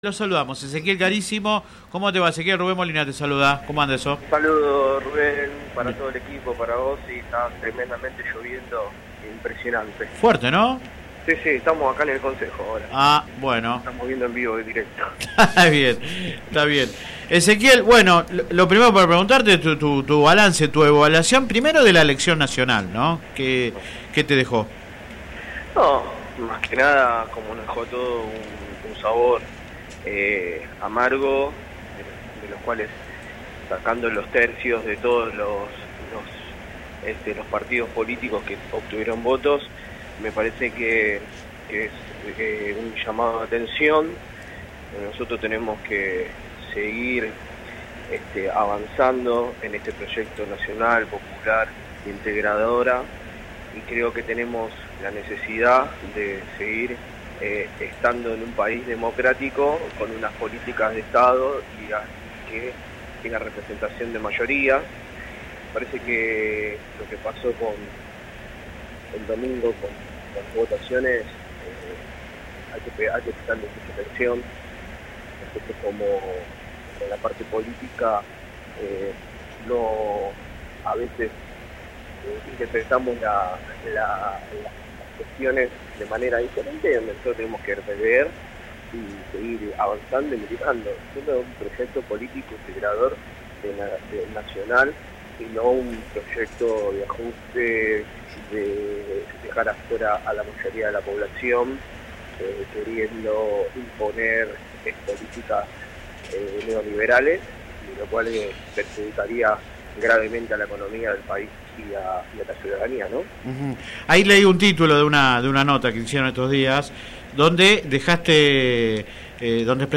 El titular de La Bancaria y concejal de UP en Lomas de Zamora, Ezequiel Carissimo, manifestó que el resultado electoral del domingo es “un llamado de atención”, aunque se mostró confiado de revertirlo en las elecciones generales de octubre. El dirigente gremial habló en el programa radial Sin Retorno (lunes a viernes de 10 a 13 por GPS El Camino FM 90 .7 y AM 1260).
entrevista radial